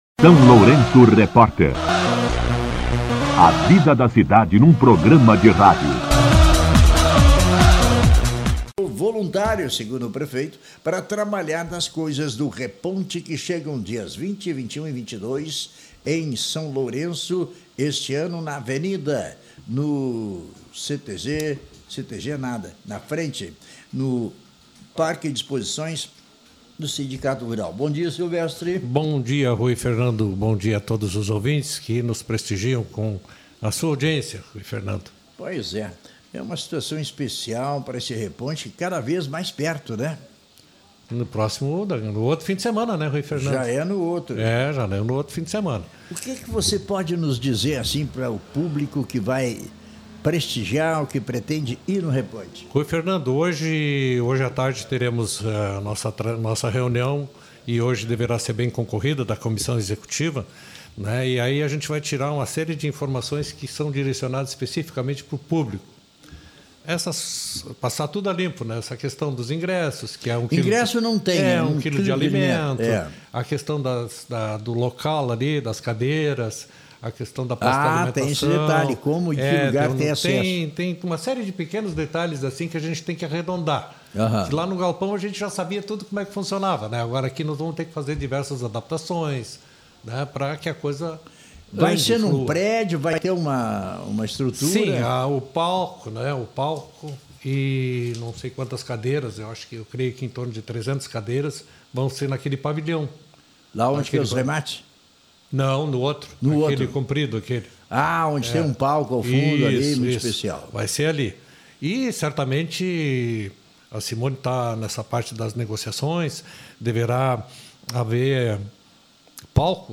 Em entrevista ao SLR RÁDIO, ele convidou toda a comunidade a prestigiar o festival.